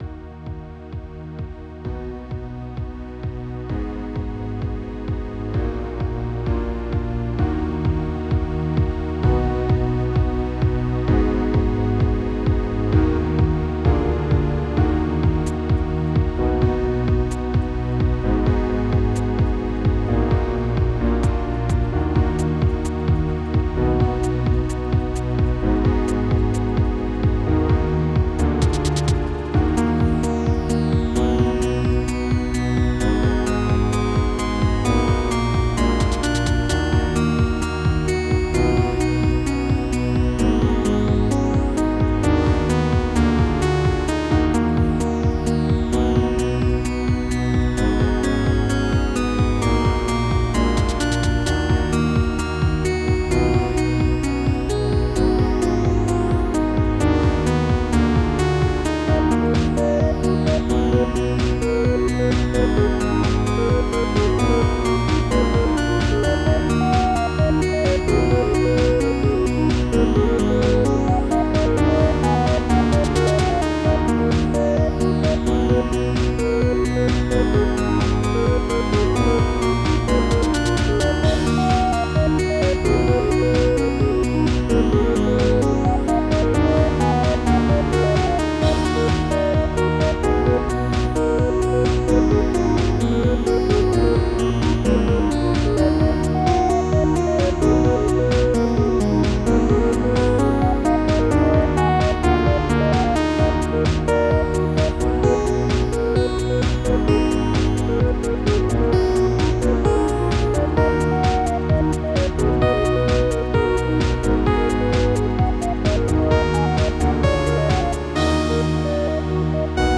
Title Late Bird Opus # 616 Year 2025 Duration 00:03:58 Self-Rating 3 Description Just when you thought you were going to be on time. mp3 download wav download Files: wav mp3 Tags: Percussion, Digital Plays: 78 Likes: 0